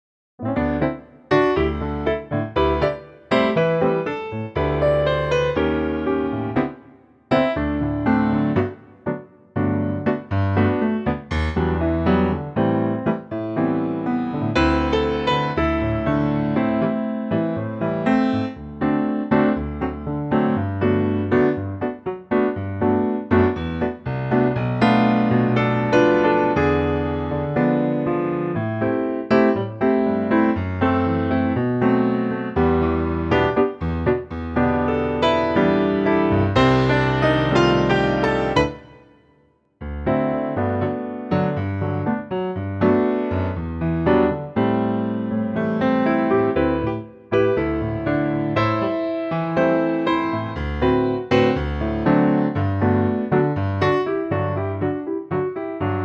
key - G - vocal range - A to D